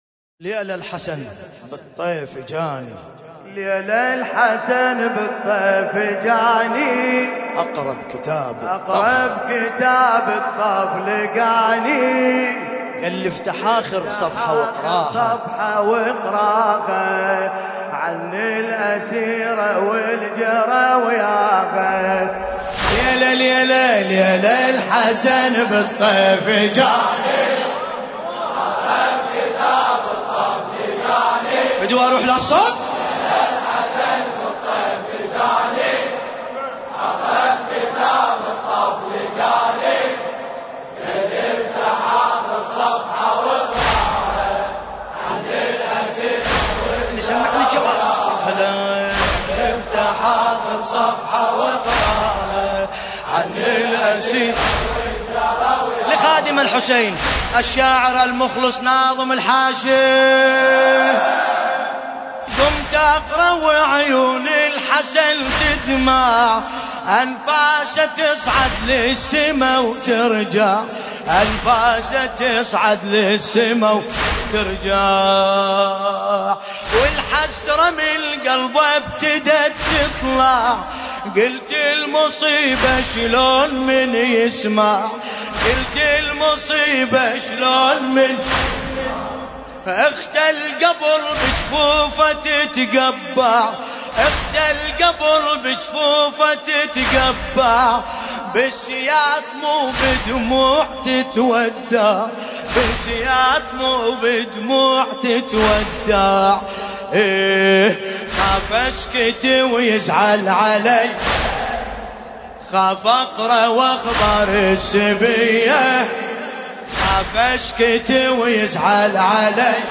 ملف صوتی ليله الحسن بصوت باسم الكربلائي
طور : يالماتجيني المناسبة : رثاء الامام الحسن (ع)